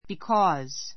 because 中 A1 bikɔ́ːz ビ コ ー ズ 接続詞 ❶ (なぜなら) （～が） ～だから , （～が） ～なので He is absent because he is ill.